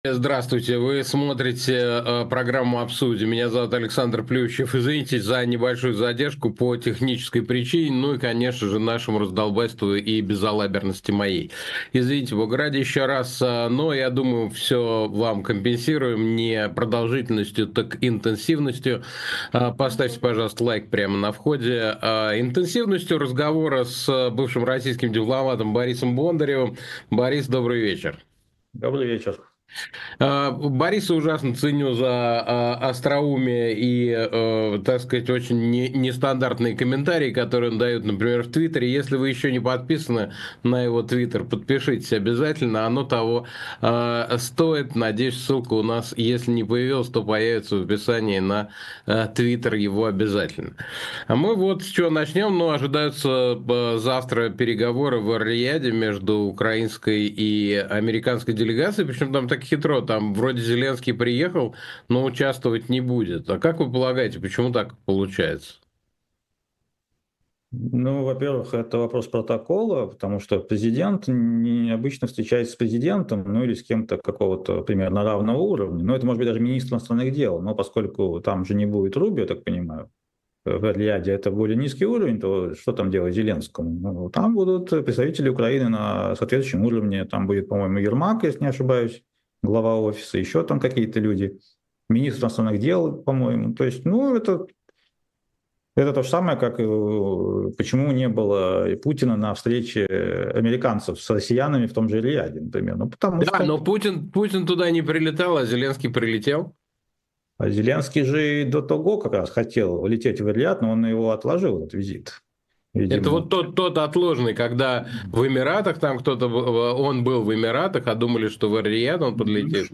Эфир ведёт Александр Плющев
В программе «Обсудим» в прямом эфире говорим о самых важных событиях с нашими гостями. Гость сегодняшнего выпуска — бывший российский дипломат Борис Бондарев, с которым мы обсудим перспективы переговоров между США и Украиной, реакцию Китая на торговое давление от США, конфликт вокруг Starlink и другие главные темы.